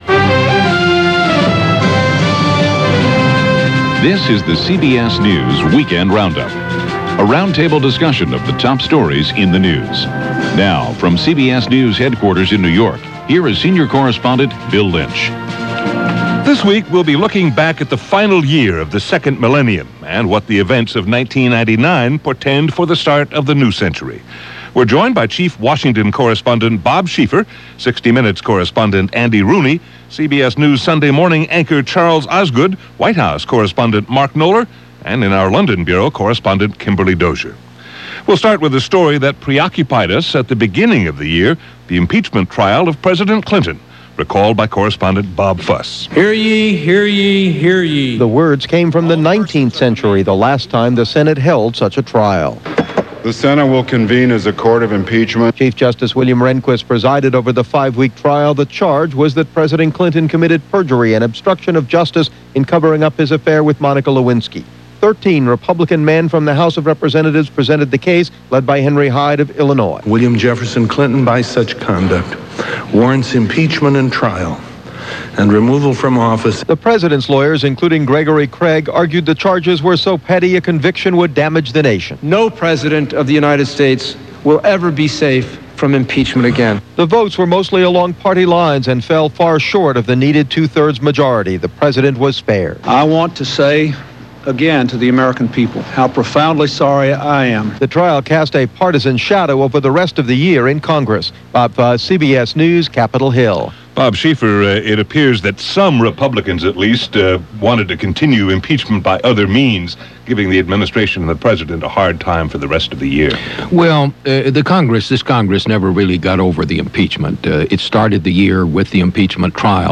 Y2K Hysteria, A Clinton Impeachment, A Place Called Columbine - 1999 - Past Daily Thousand Yard Stare - CBS Weekend Roundup.